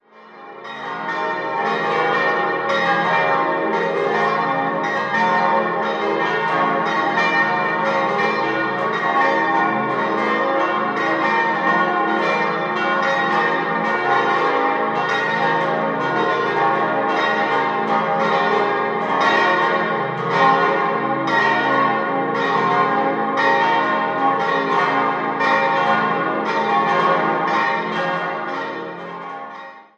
6-stimmiges Geläute: cis'-dis'-fis'-gis'-ais'-h'
Martinsglocke gis' 100 cm 1951 Bachert, Heilbronn
Michaelsglocke ais' 90 cm 1951 Bachert, Heilbronn
Marienglocke h' 92 cm 1480 Biberacher Gießhütte